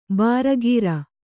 ♪ bāragīra